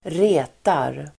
Uttal: [²r'e:tar]